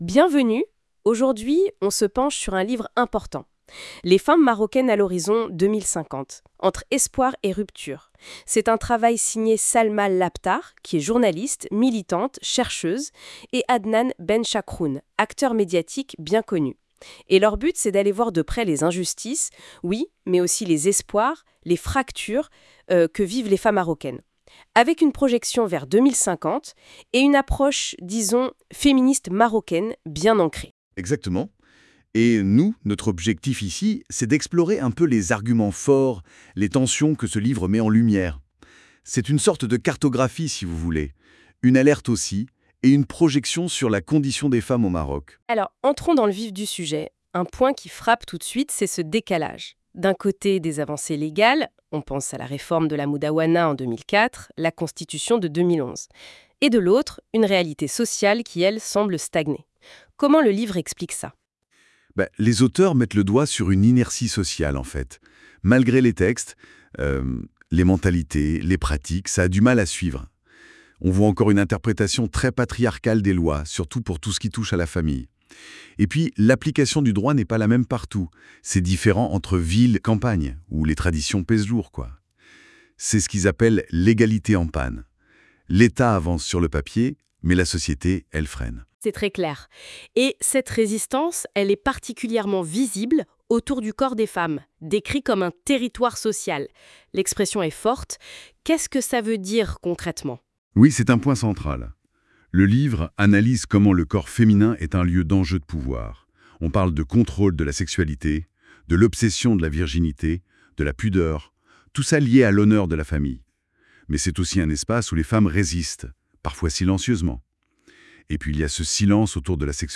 Débat sur le livre (15.47 Mo) 1.